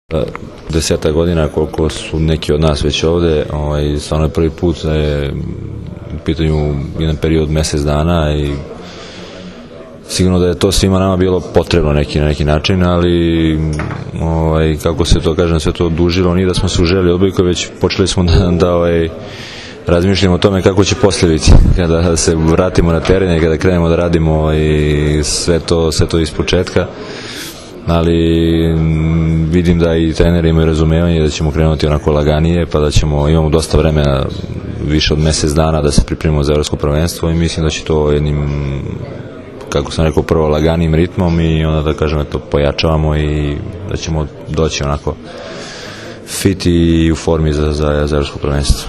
IZJAVA DRAGANA STANKOVIĆA, KAPITENA SRBIJE